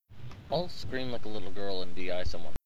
Di gril scream